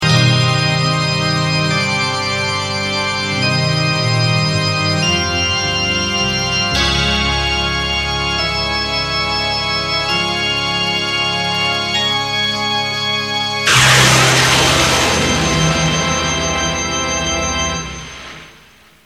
INSTRUMENTAL during which JESUS is led away and crucified